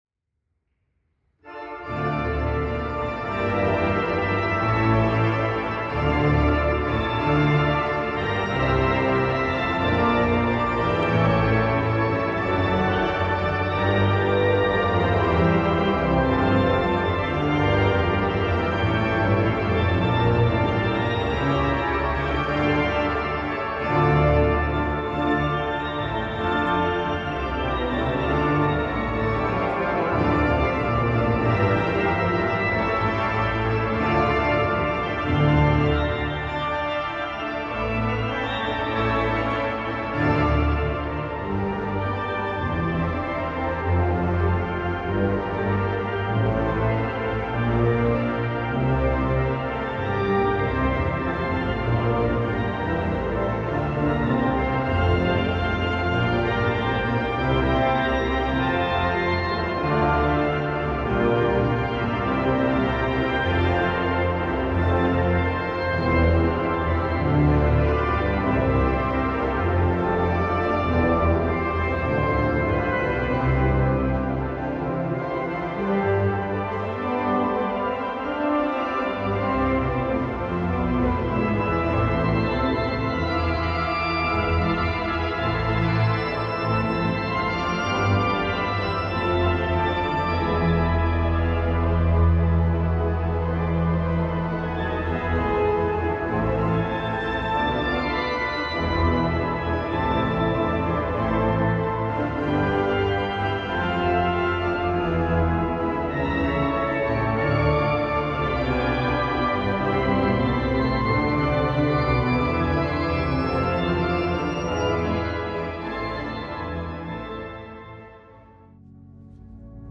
Deze (niet professionele) opnames geven een indruk van de sfeer en mogelijkheden van verschillende orgels op diverse locaties.
Een live opname van de Johannus Ecclesia T-355 met 20 kanalen audio, vanuit de prachtige St. Maartenskerk in Tiel.
Een korte compilatie om een indruk te krijgen van de verschillende klankkleuren. U hoort hier zowel het Bätz orgel, als het digitale kerkorgel.